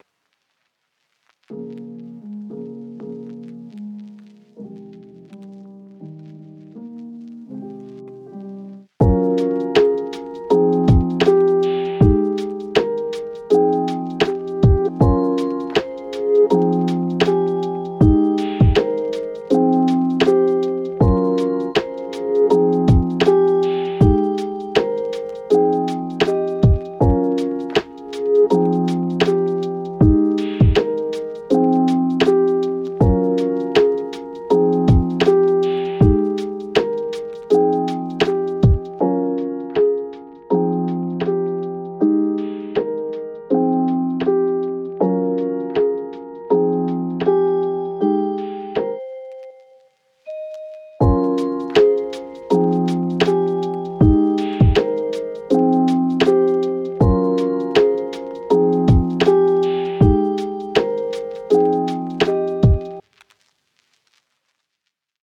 Lo-fi, blue sky, boom bap, smooth rap.